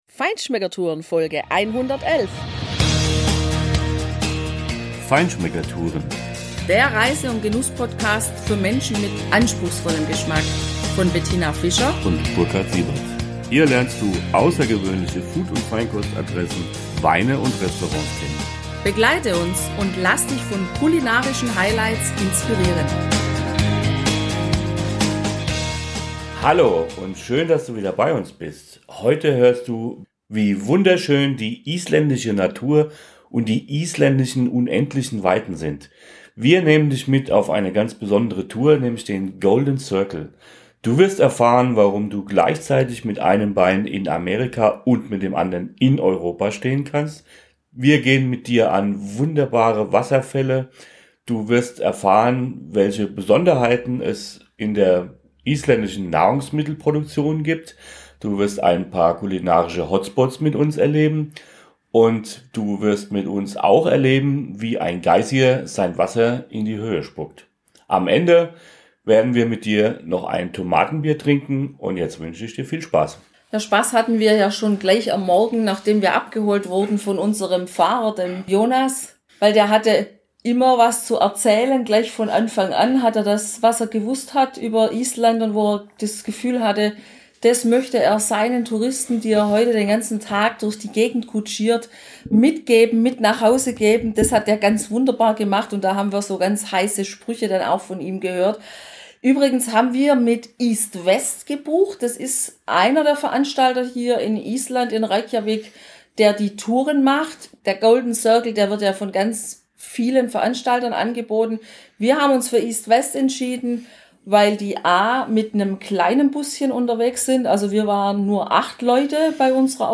Hör unsere Live-Verkostungsnotizen aus der Location unterhalb der Hallgrimskirkja!